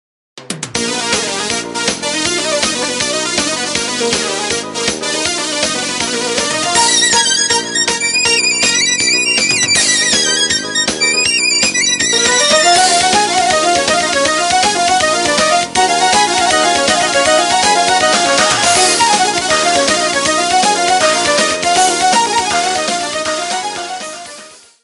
Рингтон Азербайджанский народный танец